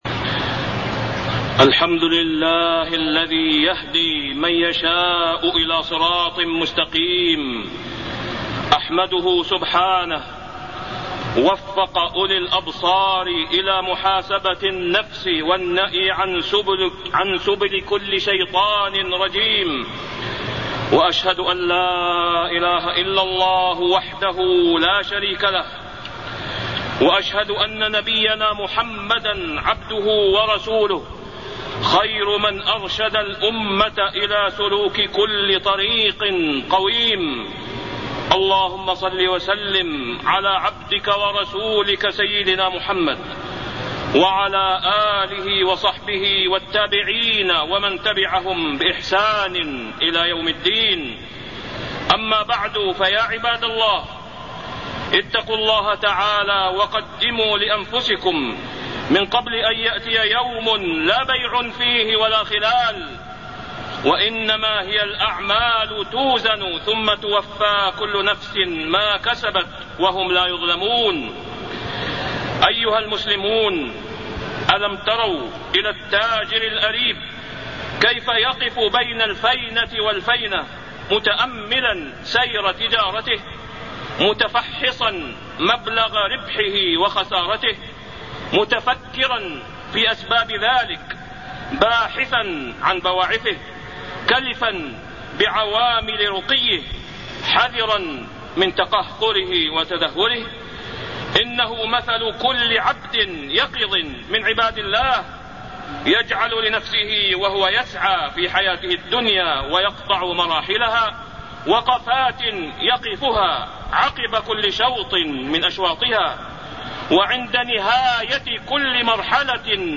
تاريخ النشر ٥ جمادى الآخرة ١٤٢٢ هـ المكان: المسجد الحرام الشيخ: فضيلة الشيخ د. أسامة بن عبدالله خياط فضيلة الشيخ د. أسامة بن عبدالله خياط محاسبة النفس